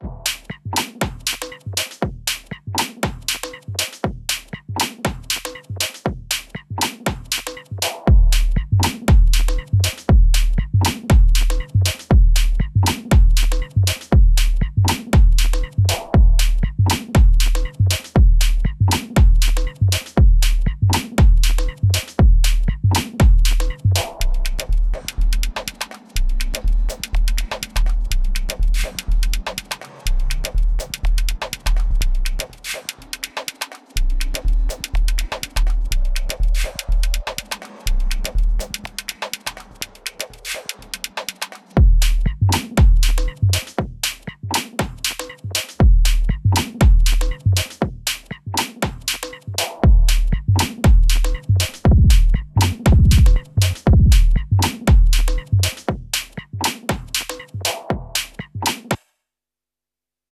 Working on some OT beatzsszzzszz :drum: